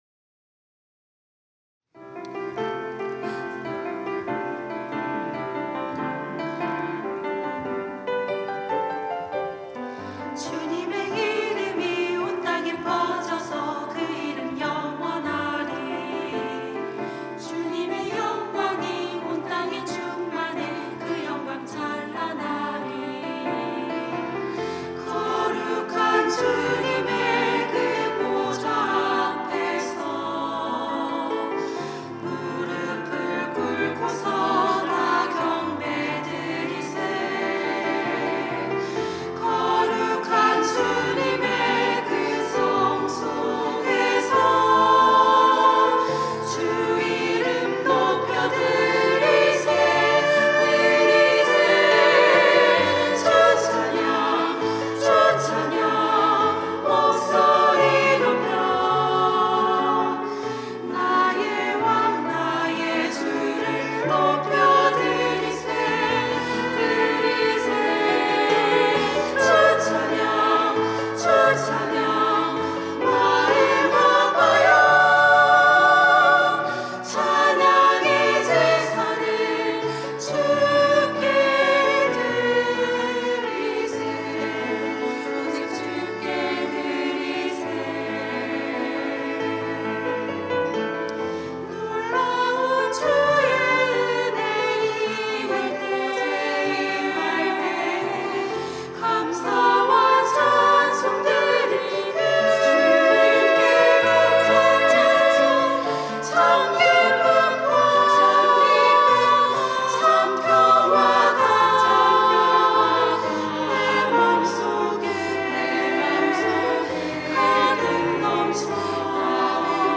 찬양대 글로리아